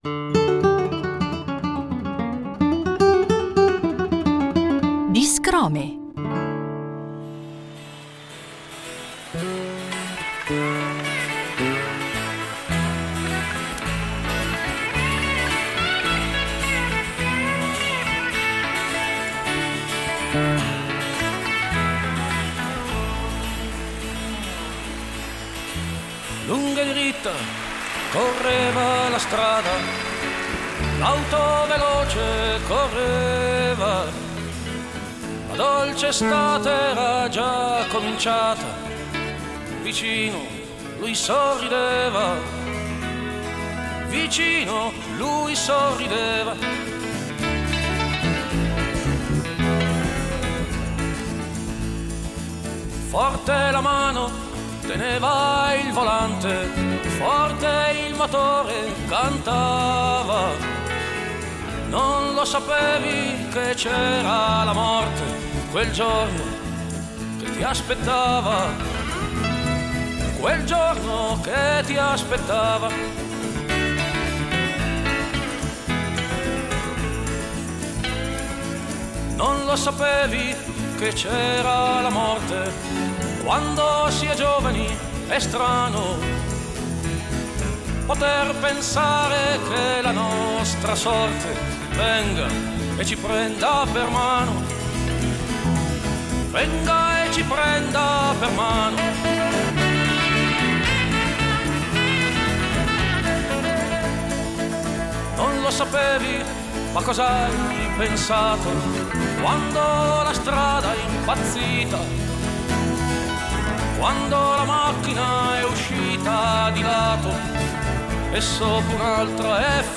Una preziosa serie di chicche tratte dai nostri archivi: il grande cantautore emiliano Francesco Guccini introduce a modo suo alcune delle sue canzoni più note e amate dal pubblico.